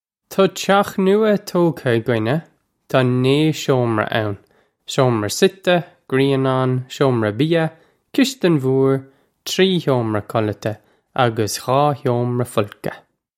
Pronunciation for how to say
Taw chyokh noo-a toe-ka ug-innya. Taw nay showm-ra ow-n: showm-ra sit-eh, green-awn, showm-ra bee-ya, kish-tin vore, tree hyo-mra kul-ata a-gus ghaw hyo-mra full-ka.
This is an approximate phonetic pronunciation of the phrase.